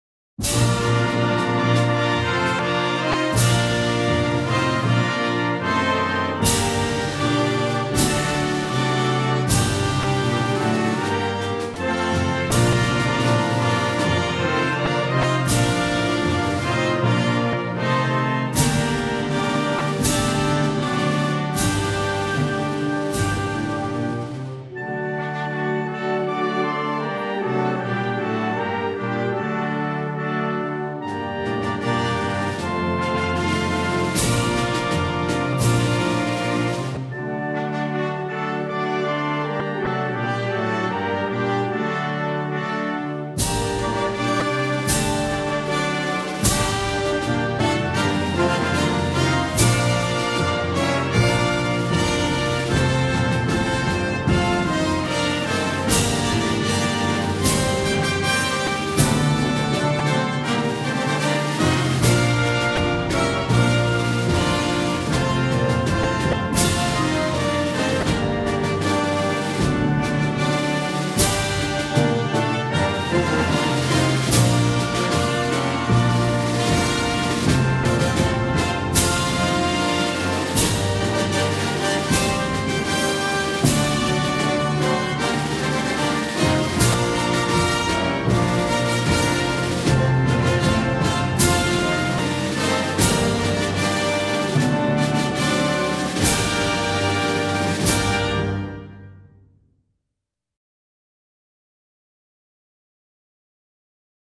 Instrumentalversion 2 (1,6 MB .mp3)